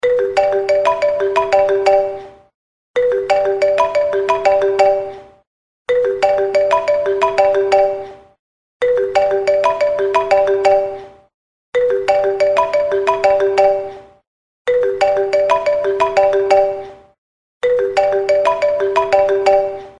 • Качество: 128, Stereo
ксилофон